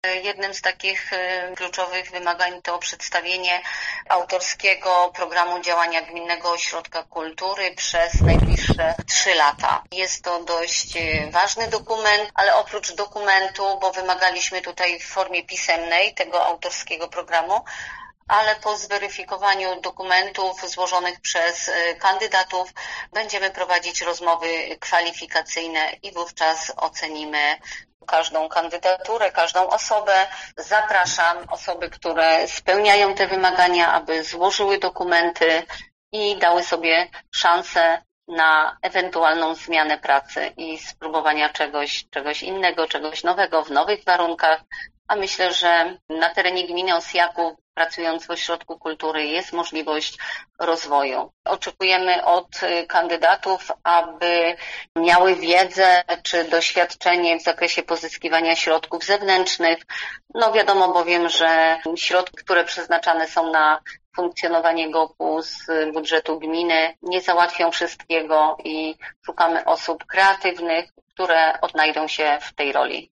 Myślę, że na terenie gminy Osjaków, w ośrodku kultury, jest możliwość rozwoju – mówi Renata Kostrzycka, burmistrz Osjakowa.